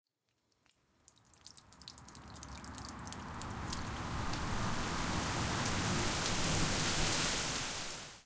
rainStart.ogg